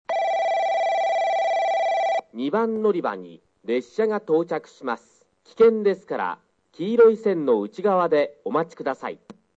スピーカー：川崎型
接近放送（男性）　(47KB/09秒)